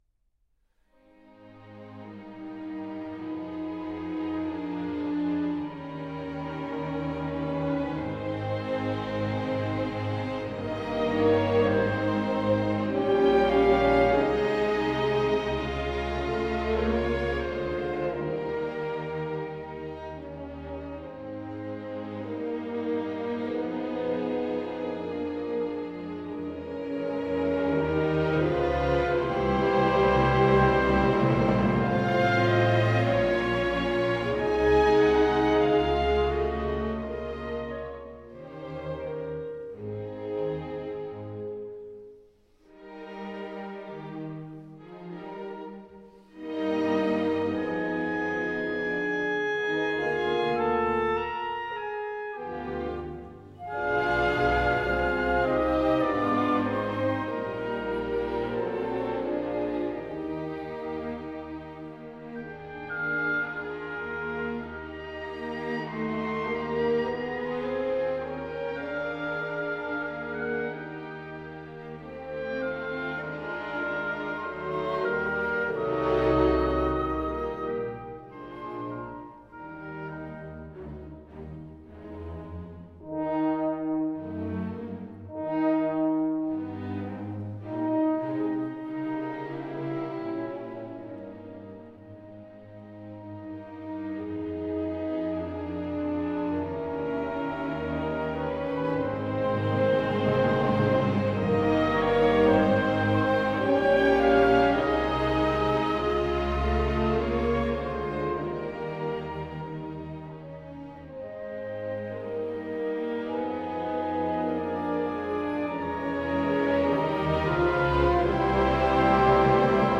Orchestra della Svizzera Italiana. Howard Shelley, conductor.